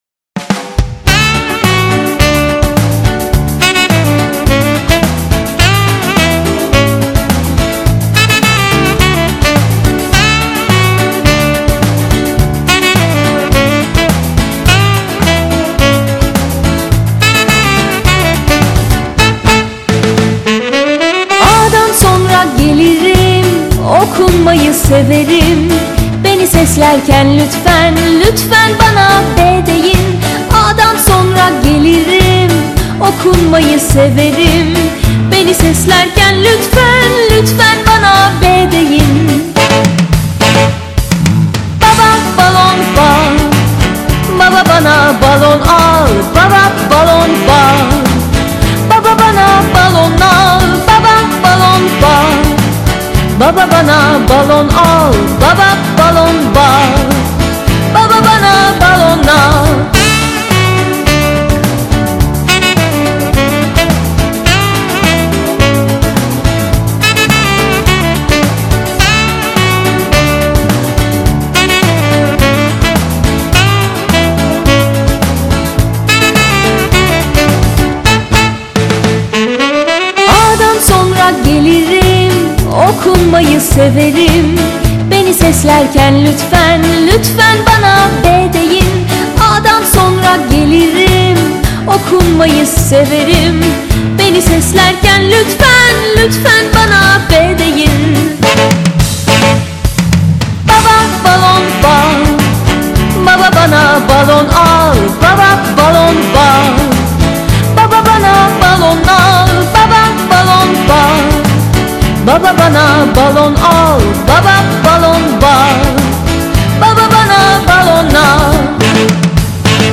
Çocuk Şarkıları